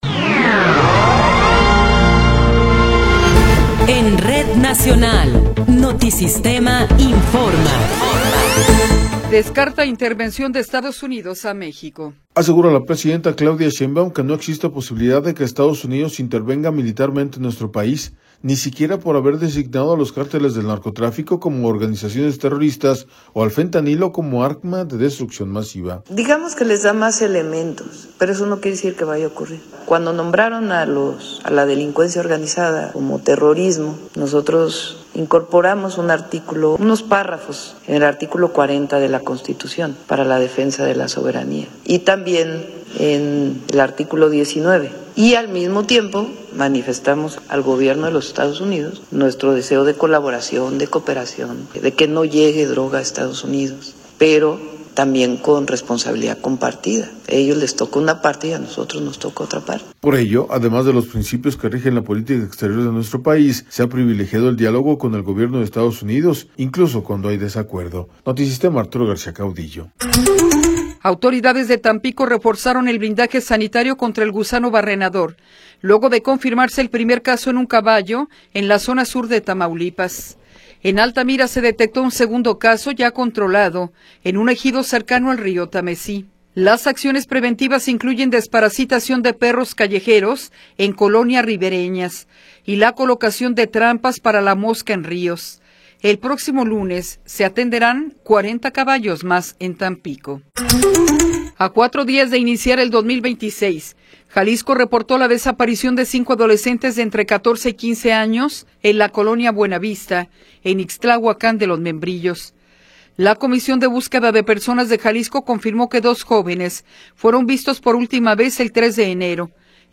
Noticiero 11 hrs. – 6 de Enero de 2026
Resumen informativo Notisistema, la mejor y más completa información cada hora en la hora.